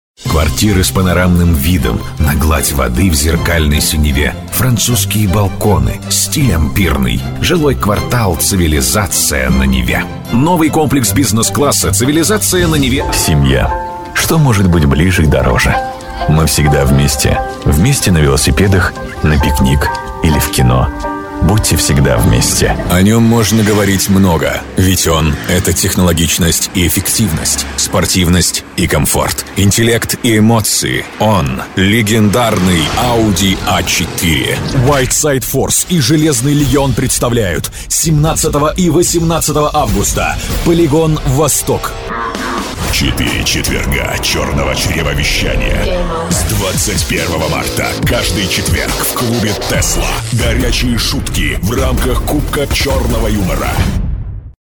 Бас-баритон, мощный, богатый обертонами, красивый голос настоящего профессионала аудиорекламы.